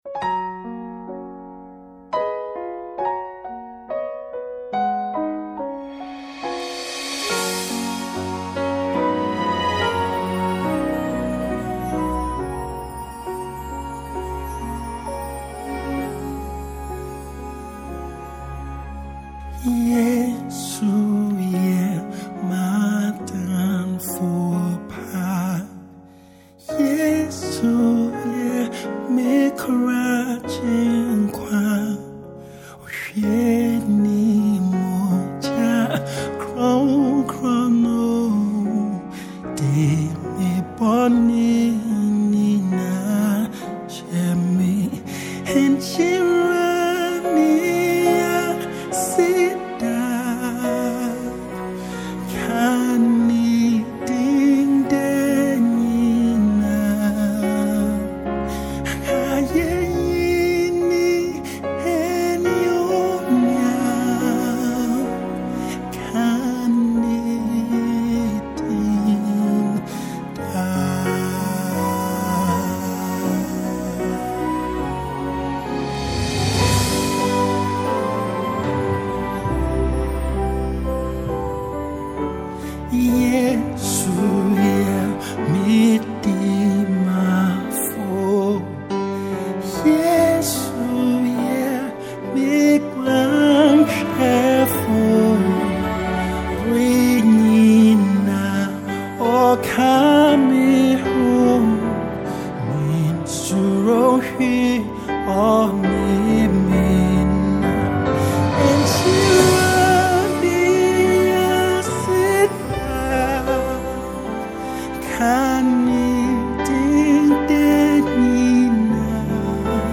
classic worship tune